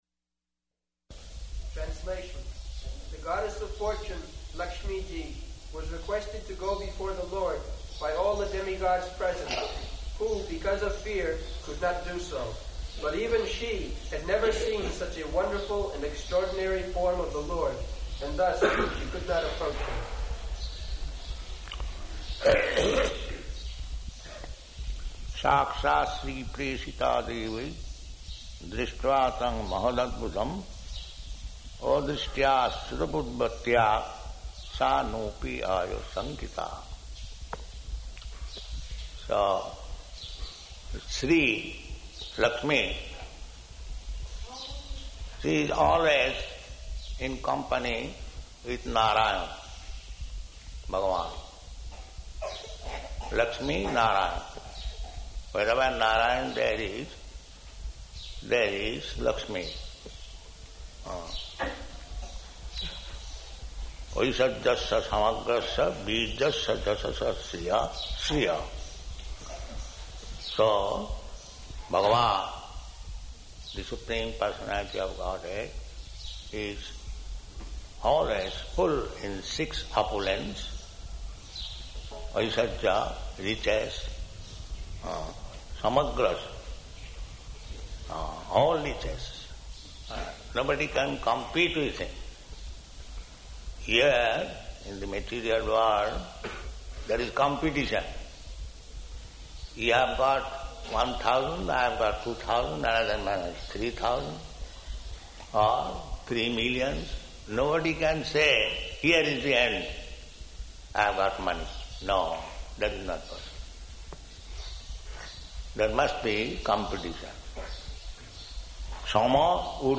Type: Srimad-Bhagavatam
Location: Māyāpur